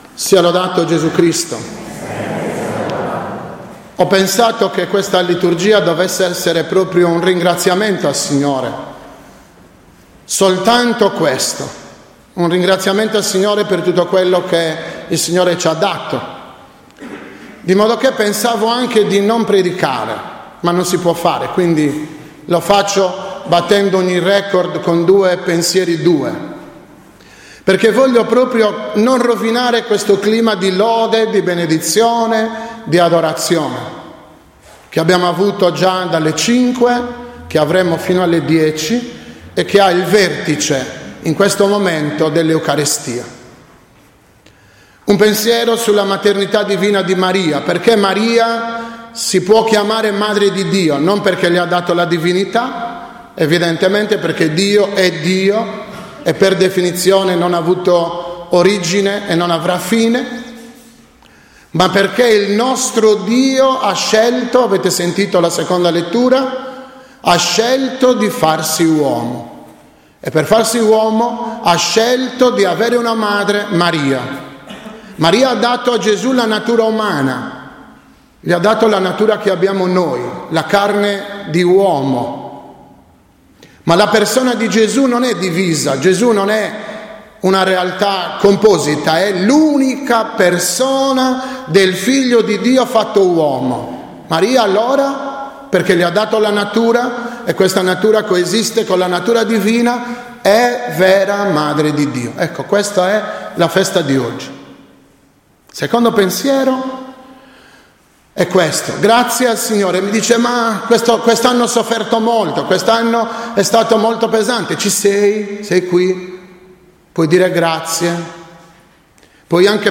31.12.2015 – OMELIA DELLA S. MESSA DELLA VIGILIA DELLA SOLENNITÀ DI MARIA SS. MADRE DI DIO – Ultimo giorno dell’anno 2015